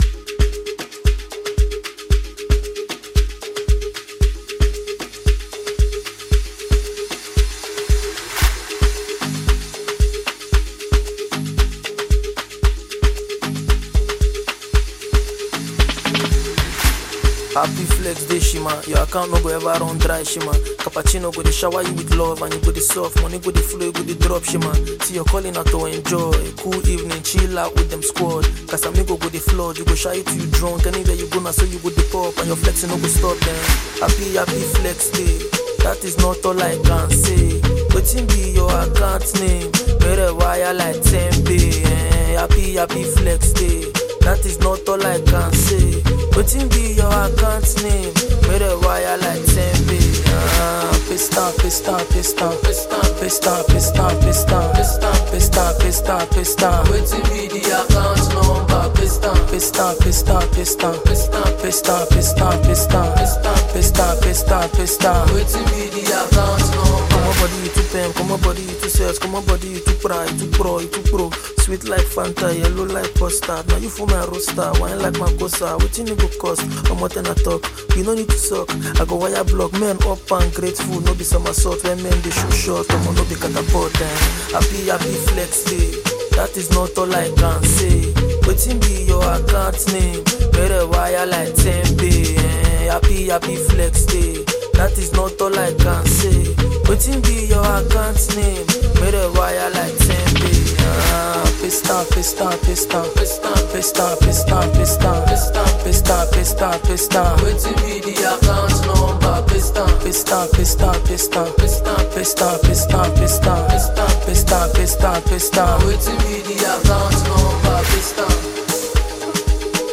Naija Music